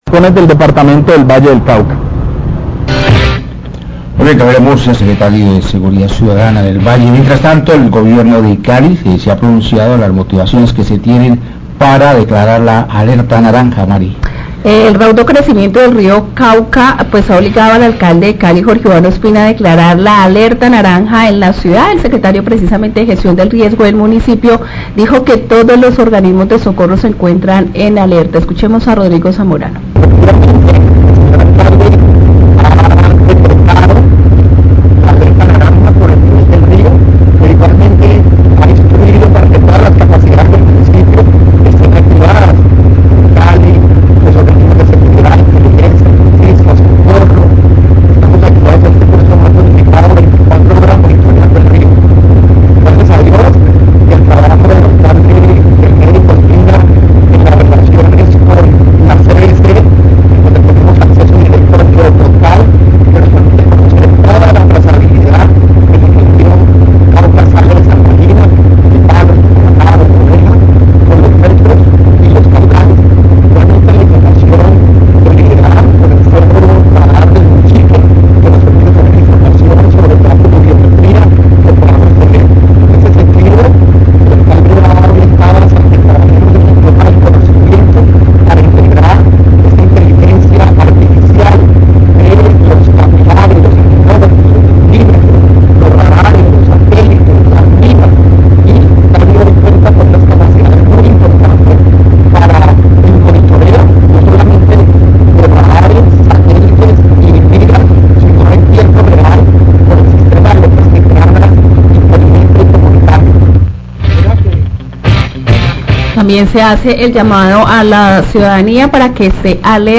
Radio
El secretario de Gestión de Riesgo, Rodrigo Zamorano, manifestó que se está realizando, gracias a la CVC, un monitoreo constante del río a su paso por la ciudad.